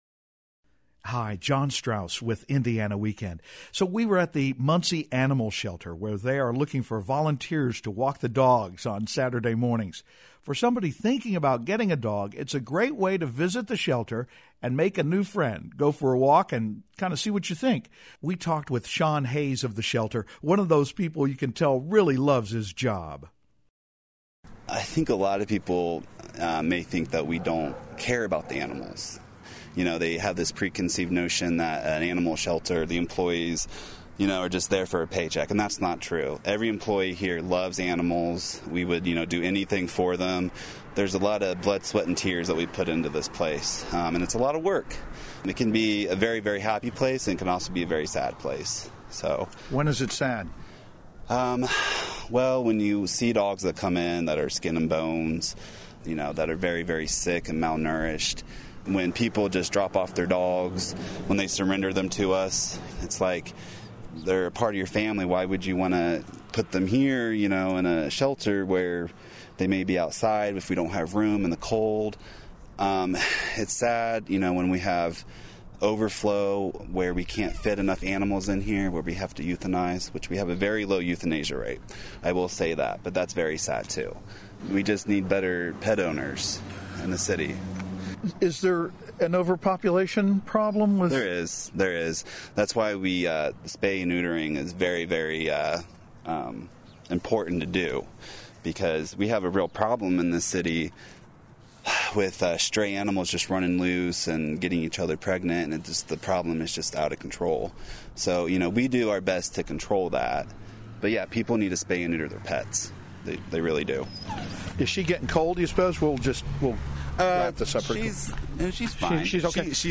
Volunteers are invited to take the dogs for Saturday morning walks. We talk with a shelter employee about how to find a four-legged friend, and with a woman just finishing her adoption of a new pet.